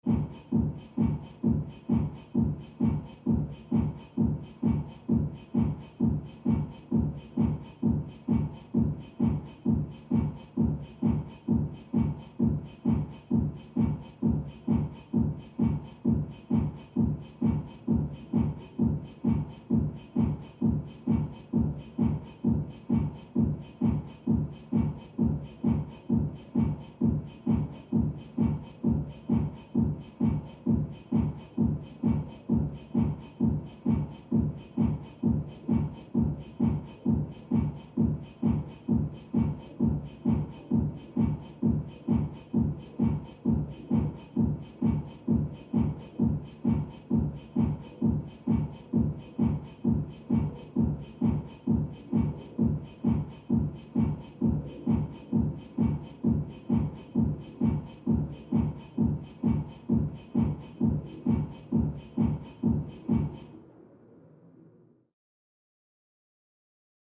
Music; Electronic Dance Beat, From Next Room.